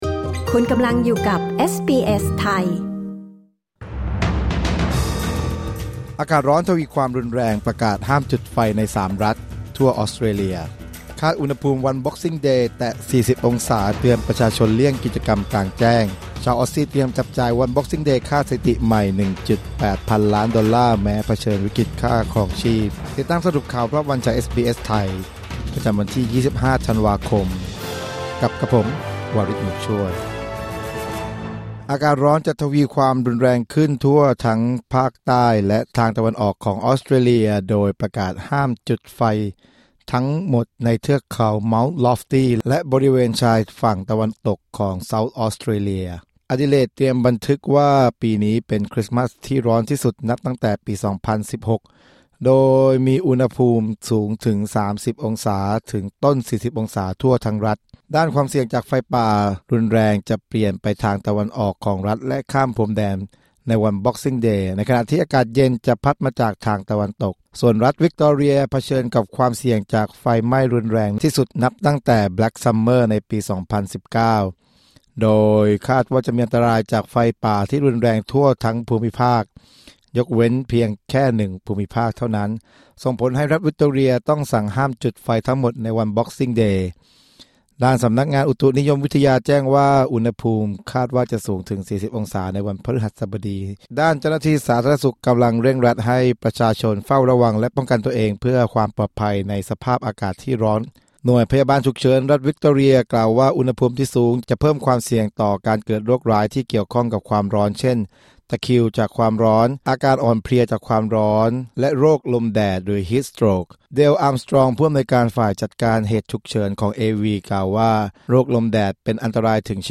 คลิก ▶ ด้านบนเพื่อฟังรายงานข่าว